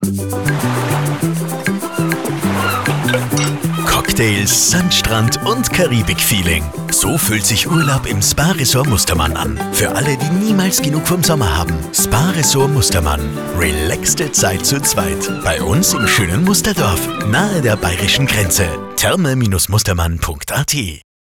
Links hört ihr die Stimme unseres Sprechers, rechts die KI-generierte Variante – ein direkter Vergleich für Ausdruck, Natürlichkeit und Emotionalität.
Radiowerbespot 03
Radiospot "Karibik"